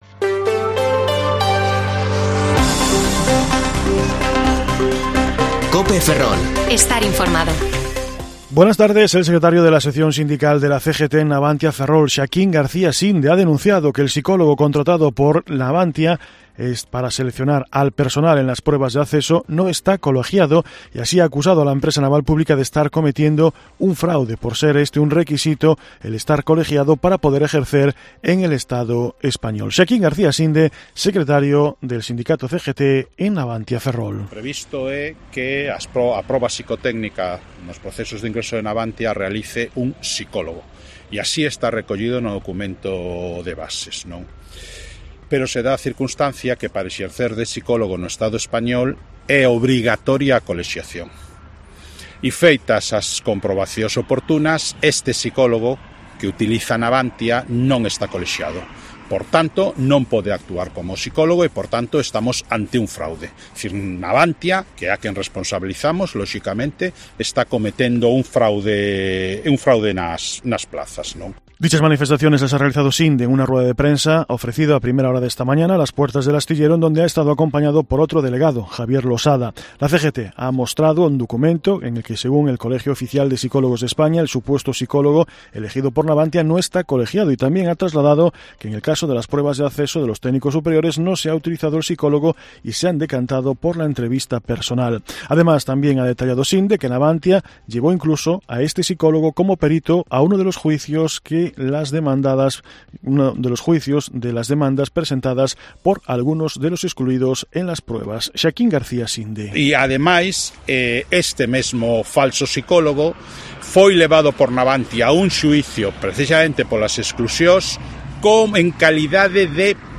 Informativo Mediodía COPE Ferrol 24/2/2022 (De 14,20 a 14,30 horas)